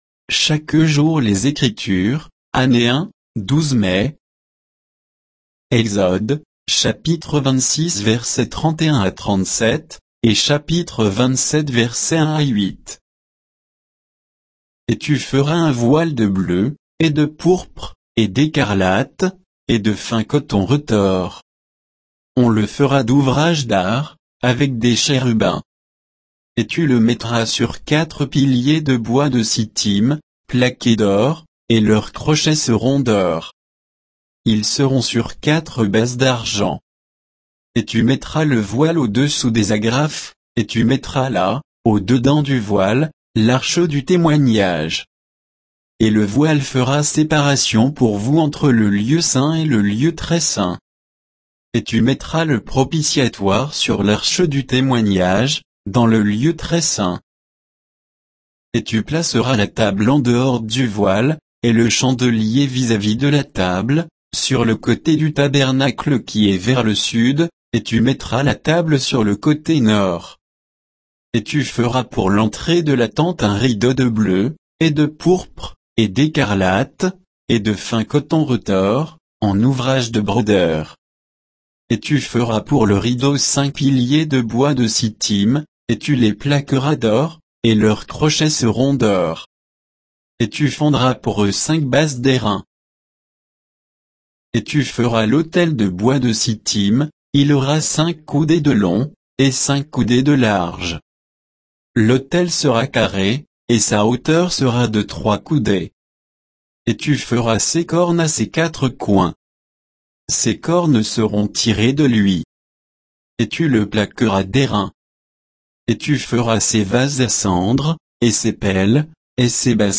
Méditation quoditienne de Chaque jour les Écritures sur Exode 26, 31 à 27, 8